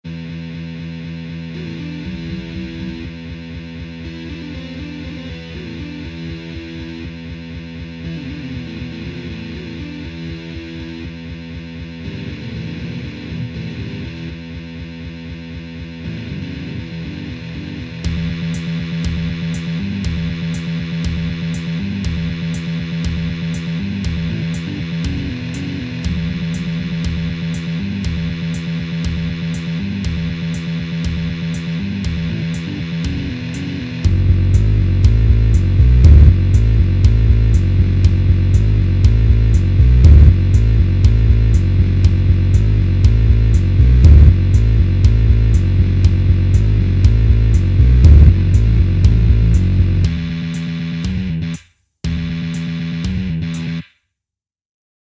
Это смешанная хрень.
TechnoMitol.mp3